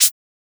Closed Hats
edm-hihat-08.wav